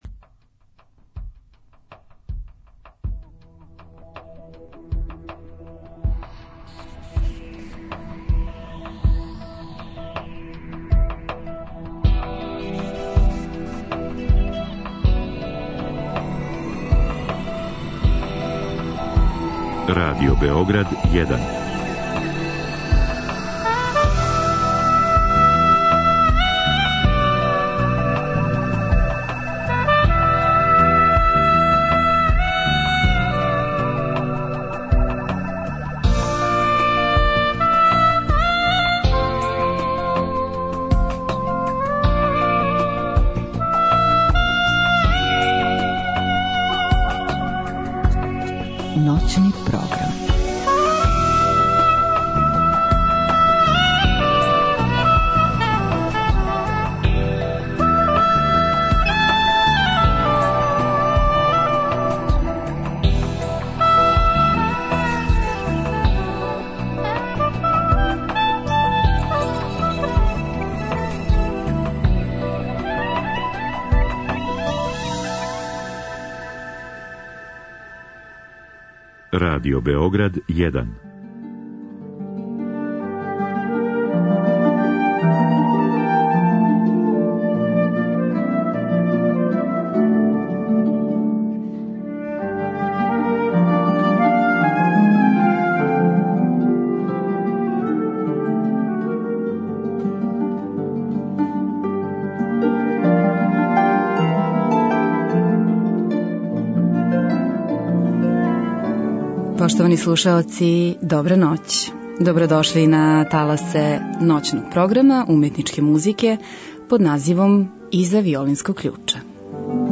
Од два сата после поноћи слушаћемо најлепше бајке преточене у музику, а изникле из пера Стравинског, Сибелијуса, Чајковског, Римски-Корсакова и Персла.